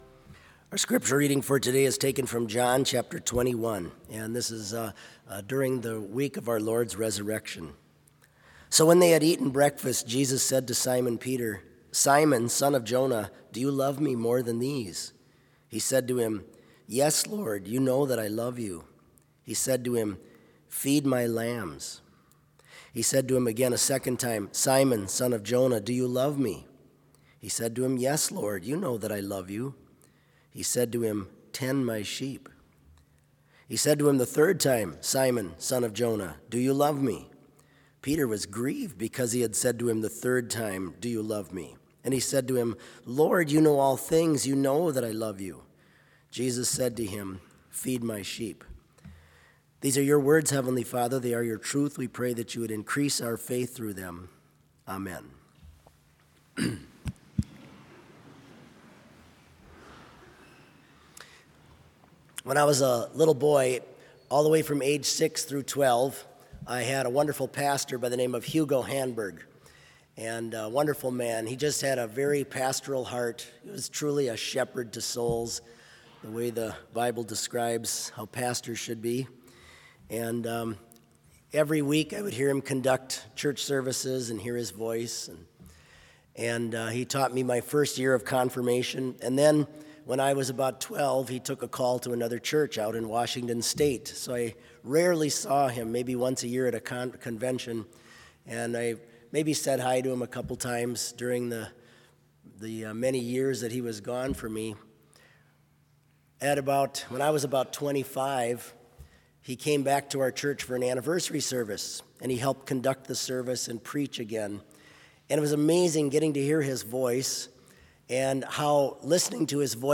Complete service audio for Chapel - January 15, 2020
Hymn 177 - I Am Jesus' Little Lamb